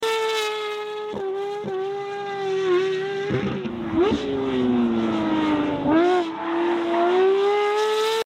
🐐 911 DESERVES 🐐 EXHAUST! sound effects free download